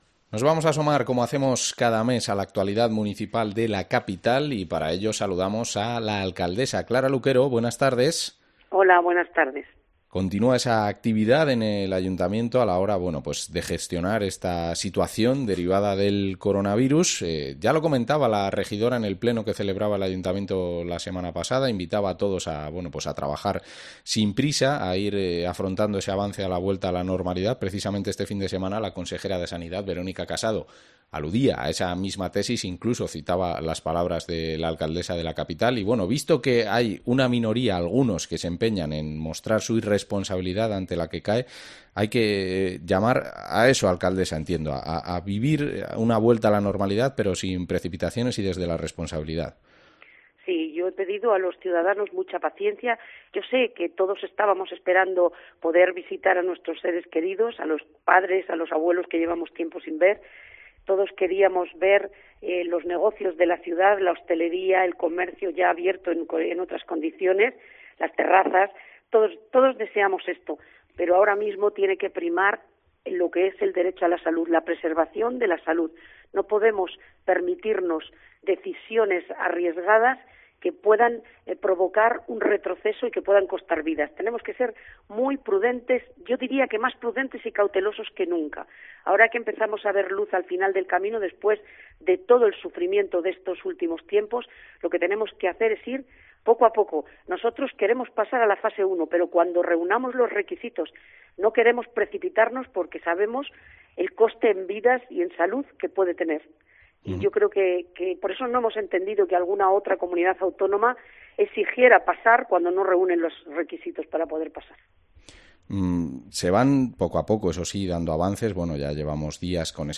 Entrevista a Clara Luquero, alcaldesa de Segovia